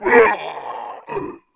monster2 / fire_king / dead_1.wav